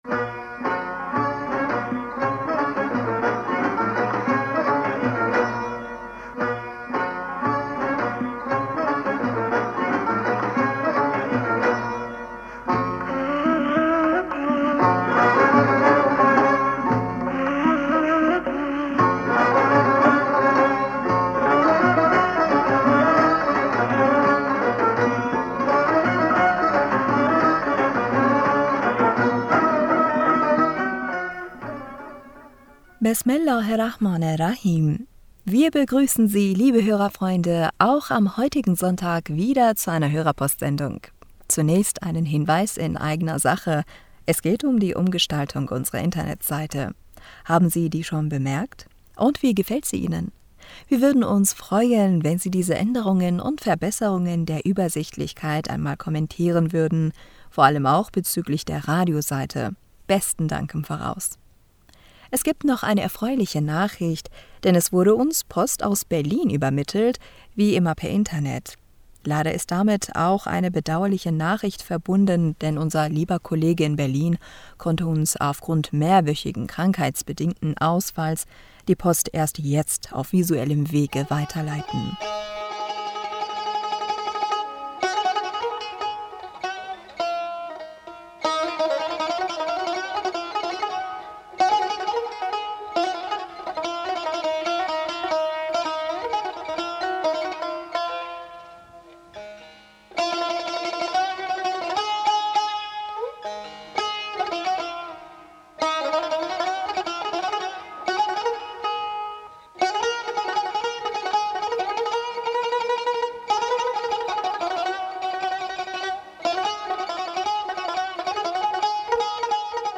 Hörerpostsendung am 13. November 2022 Bismillaher rahmaner rahim.Wir begrüßen Sie, liebe Hörerfreunde, auch am heutigen Sonntag wieder zu einer Hörerposts...